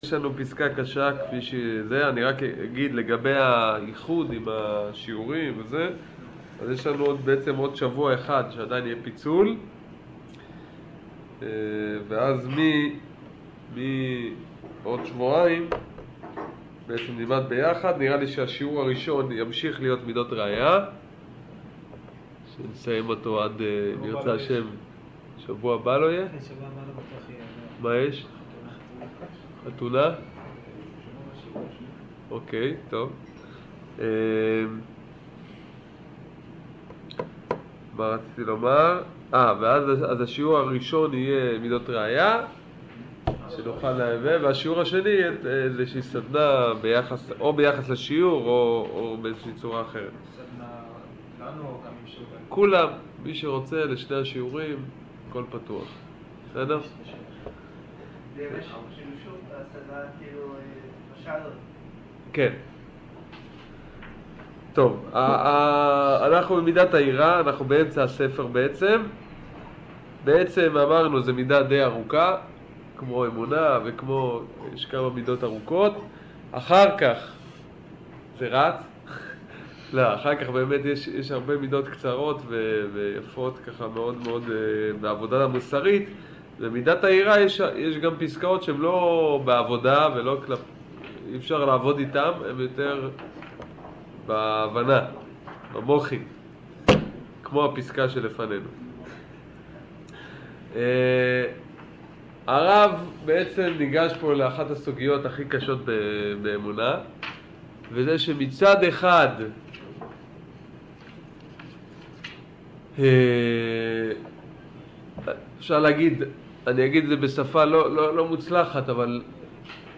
שיעור יראה ב-ג